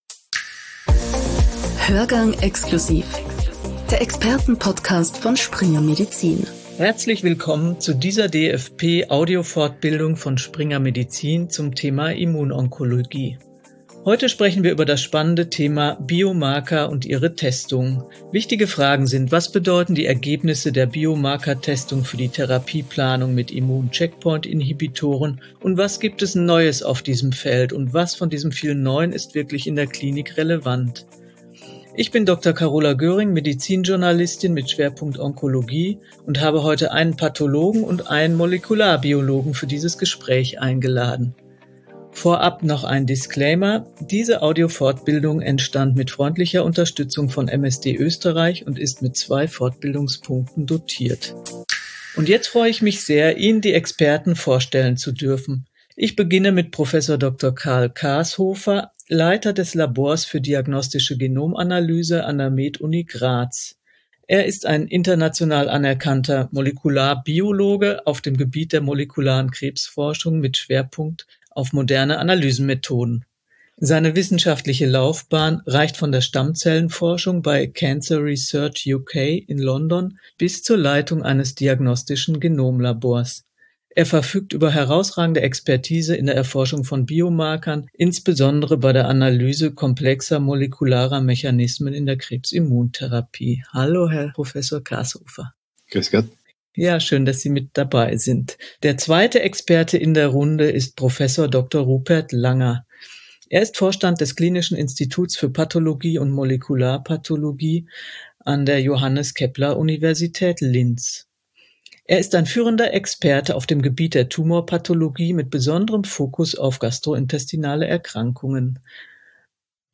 Shownotes Diese Audio-Fortbildung entstand mit freundlicher Unterstützung von MSD Österreich und ist mit zwei Fortbildungspunkten dotiert.